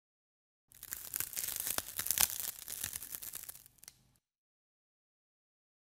Ice Crackling 2 Sound
Ice Crackling 2